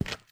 STEPS Concrete, Run 30, Long Ending.wav